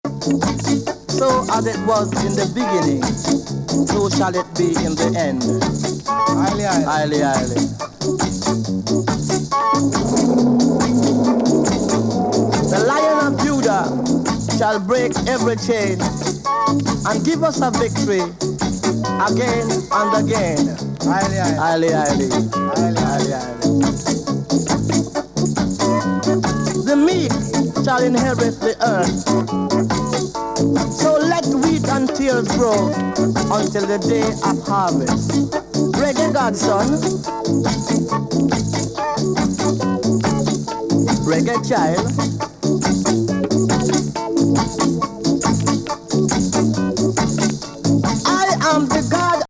ゲンSKA満載!